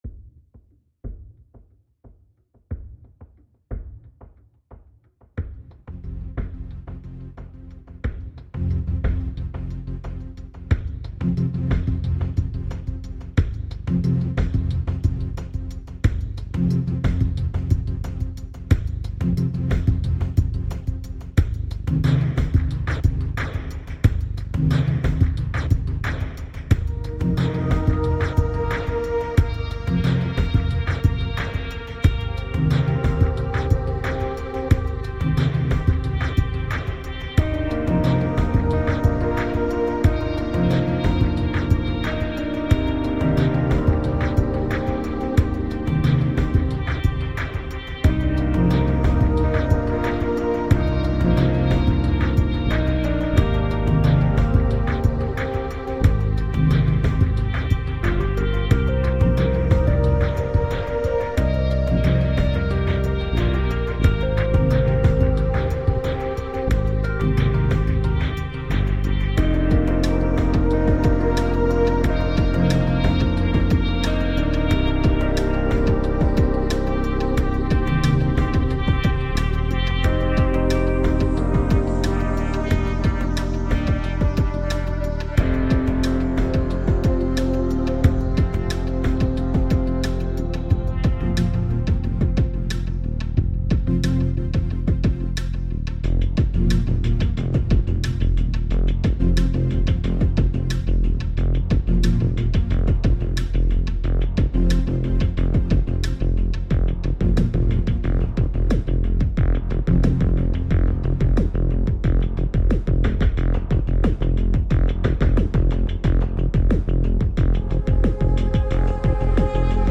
Atmospheric downtempo.
Tagged as: Ambient, Electronica, IDM, Space Music